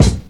break_kick_1.wav